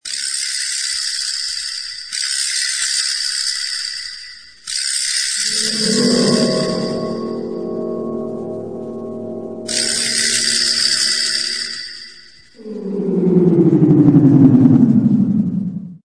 Здесь собраны аудиозаписи, которые раскрывают весь потенциал стереозвука — от успокаивающих мелодий до динамичных эффектов.
Погружение в объёмный звук через наушники